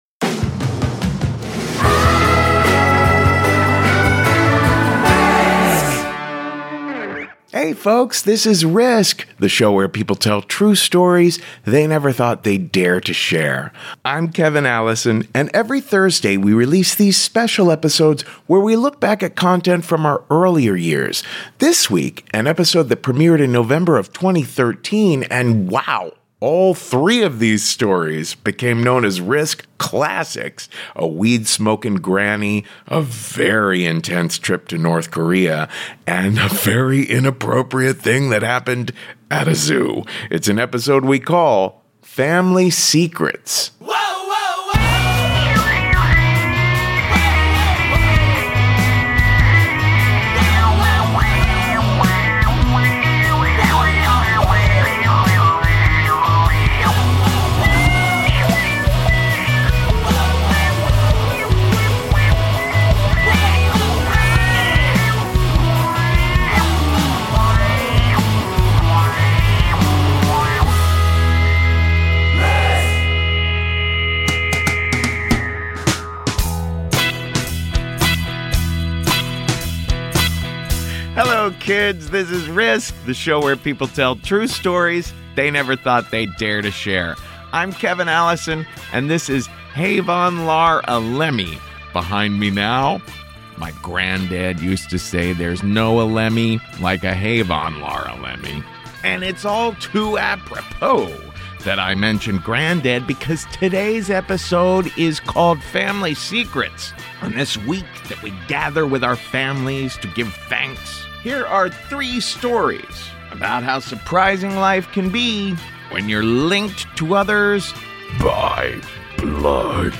🎤🎙💻Live Story
🎤🎙💻Radio Story